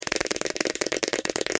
دانلود صدای حشره 6 از ساعد نیوز با لینک مستقیم و کیفیت بالا
جلوه های صوتی